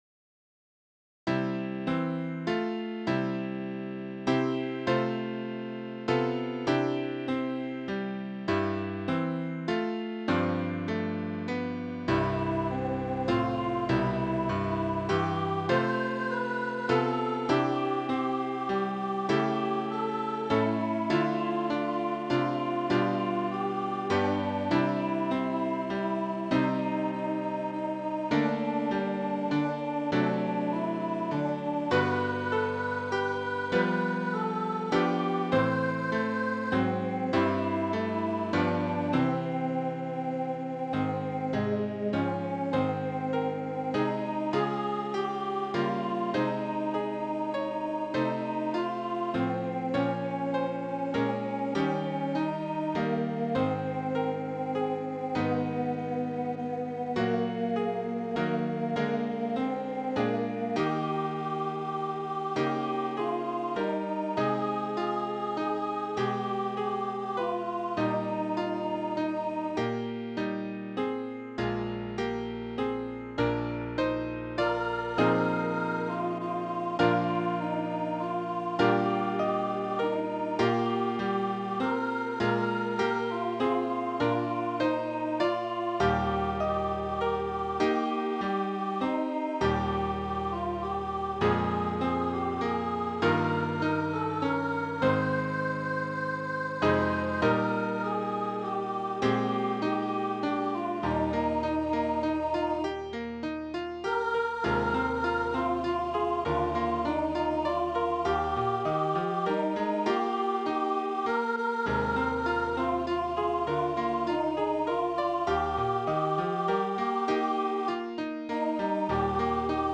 for medium low voice